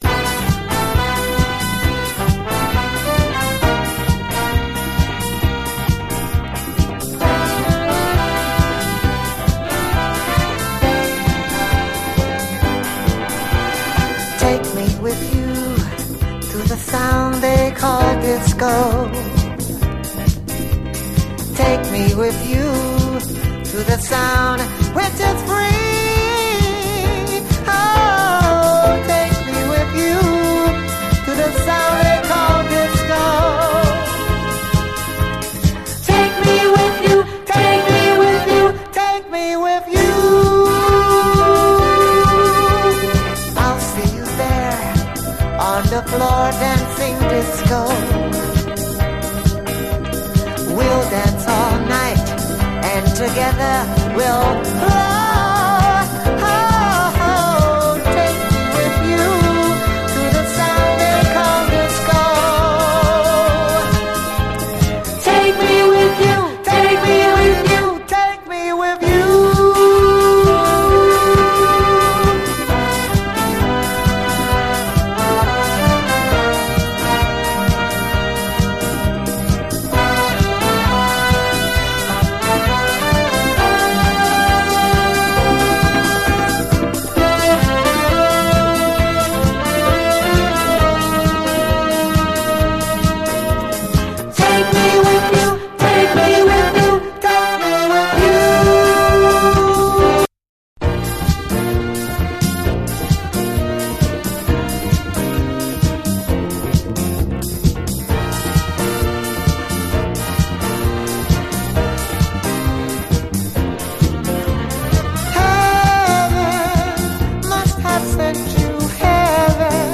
SOUL, 70's～ SOUL, DISCO, LATIN
NYラテン・ソウル界名シンガーによる、華麗なる胸キュン・ラテン・ディスコ！